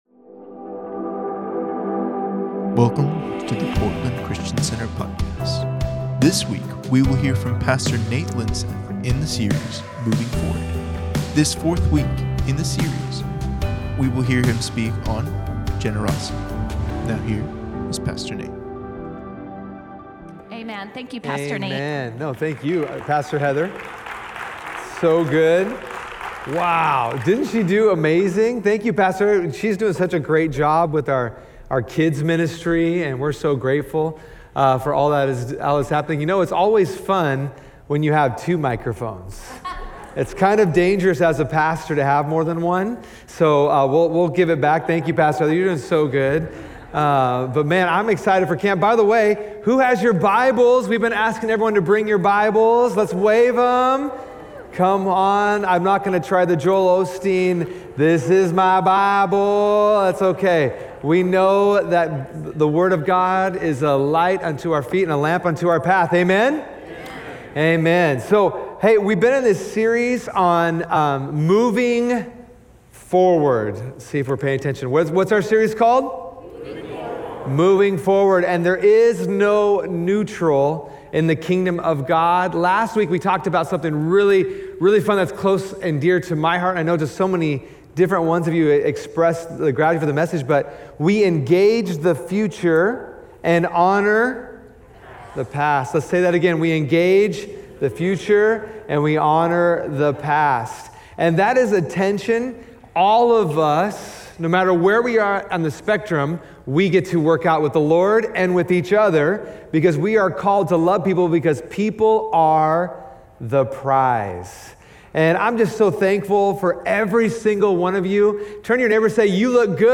Sunday Messages from Portland Christian Center Moving Forward: Generosity Aug 07 2022 | 00:38:08 1x 00:00 / 00:38:08 Subscribe Share Spotify RSS Feed Share Link Embed